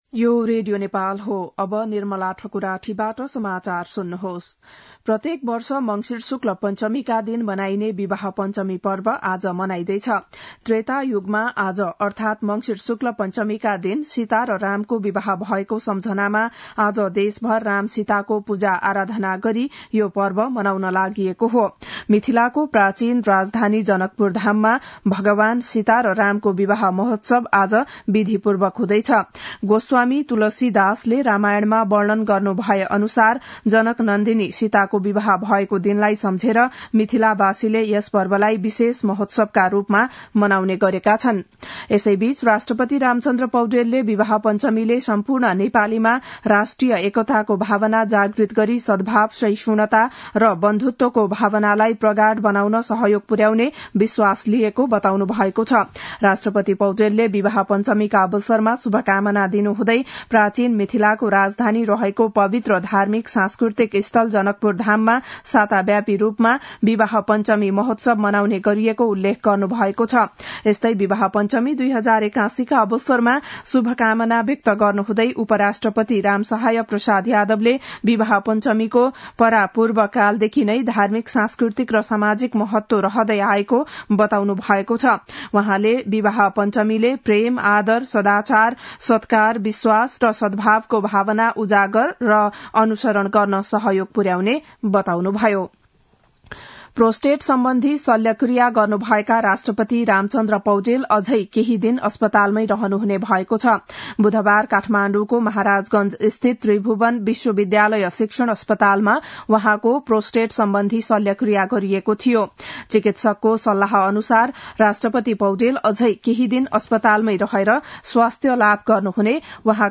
बिहान ११ बजेको नेपाली समाचार : २२ मंसिर , २०८१
11-am-nepali-news-1-5.mp3